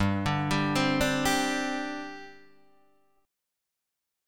Gsus4 chord